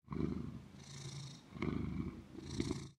1.21.5 / assets / minecraft / sounds / mob / cat / purr1.ogg
purr1.ogg